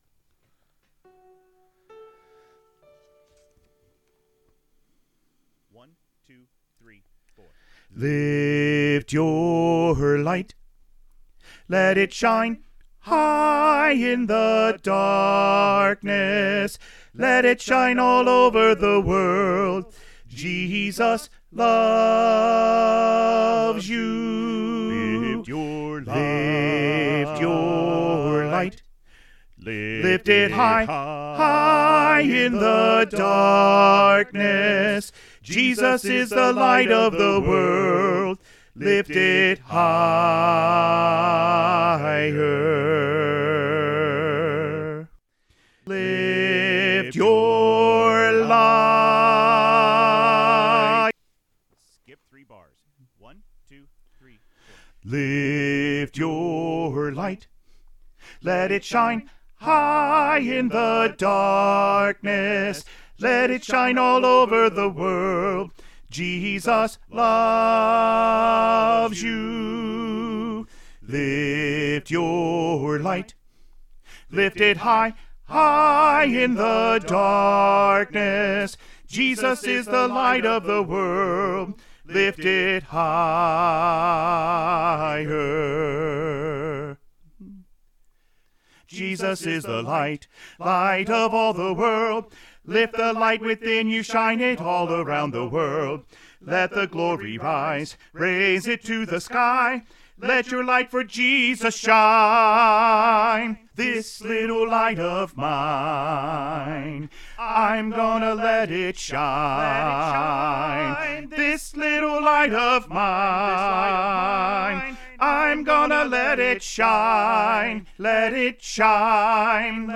Choir Music Learning Recordings
Lift Your Light - Soprano Emphasized All 4 Parts with The Soprano Part Emphasized